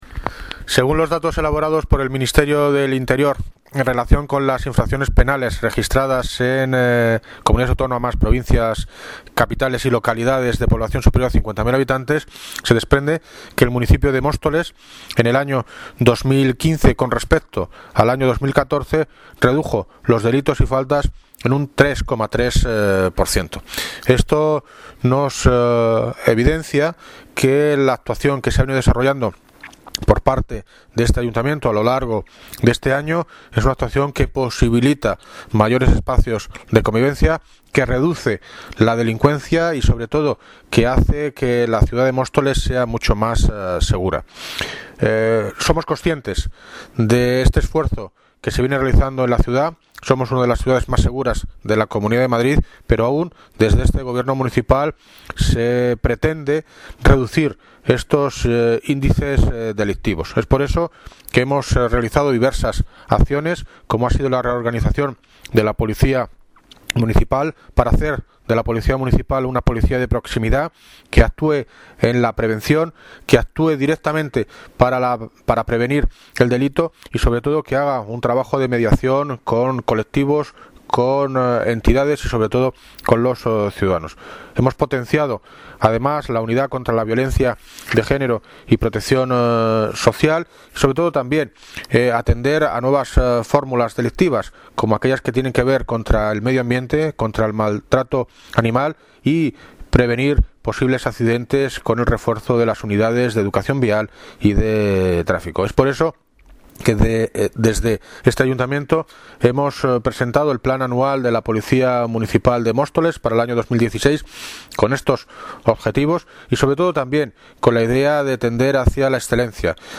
Audio - David Lucas (Alcalde de Móstoles) Sobre Reducción de Delitos en Móstoles